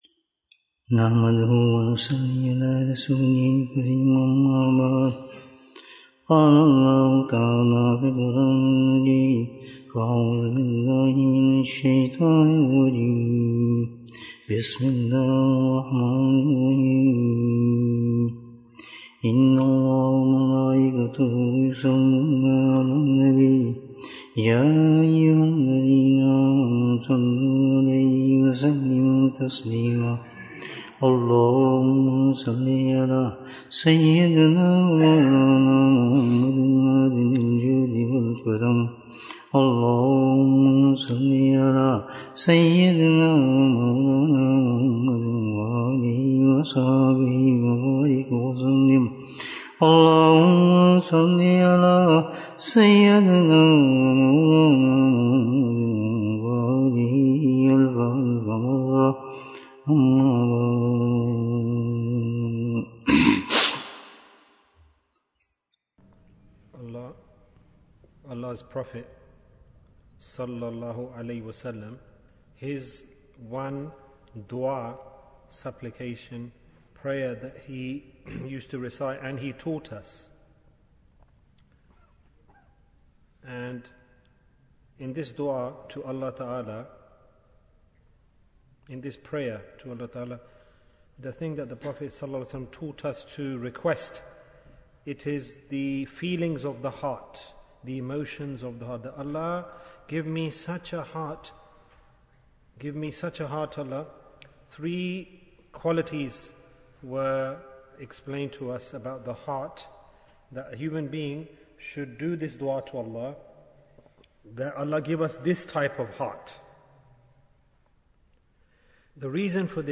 The Path of Closeness to Allah Bayan, 34 minutes15th June, 2023